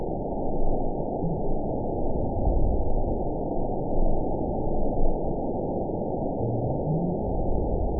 event 912549 date 03/29/22 time 08:31:28 GMT (3 years, 1 month ago) score 9.64 location TSS-AB02 detected by nrw target species NRW annotations +NRW Spectrogram: Frequency (kHz) vs. Time (s) audio not available .wav